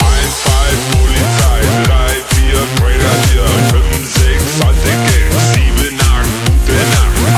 polizeiloop.ogg